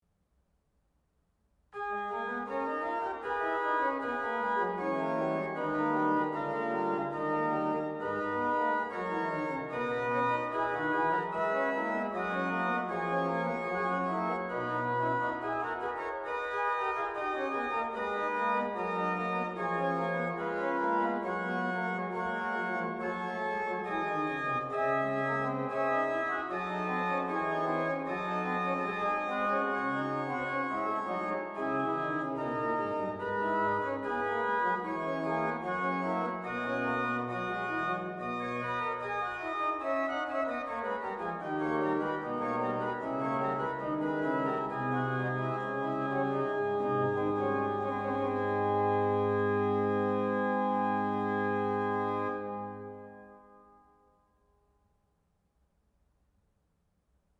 Choral et onze variations, enregistrés le 26/10/2008 sur l’orgue virtuel Silbermann (1735) de la Petrikirche de Freiberg (version wet diffuse)
HW: Pincipal 8, Viola di Gamba 8, Octava 4 – OW: Principal 8, Octava 4, Nasat 3
BWV-768-Freiberg-wet-Sei-gegruesset-04.mp3